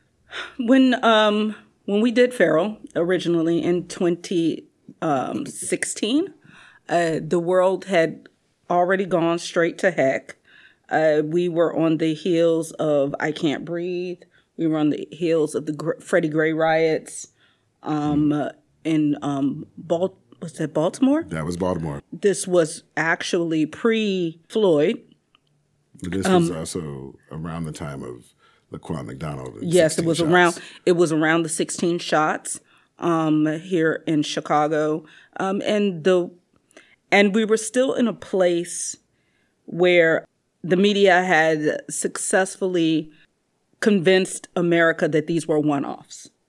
Our creative team gathers here to discuss the play and its impact.